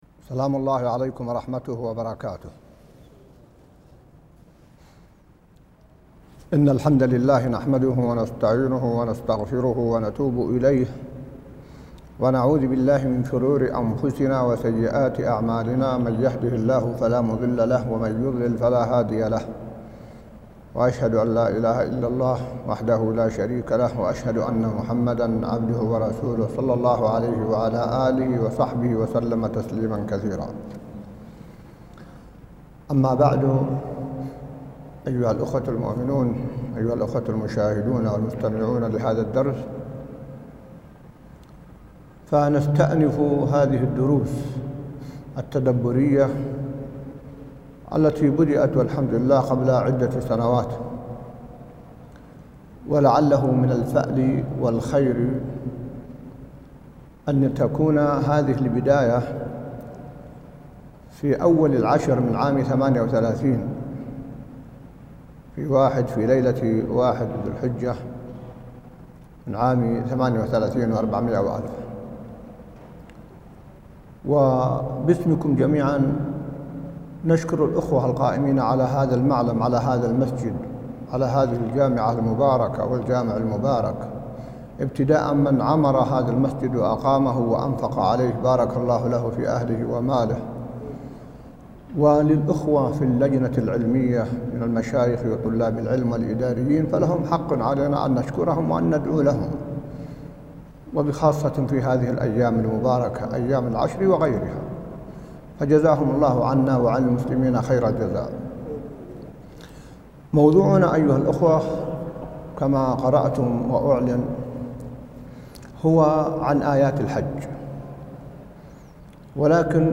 درس ليدبروا آياته 110 | وقفات مع آيات الحج | موقع المسلم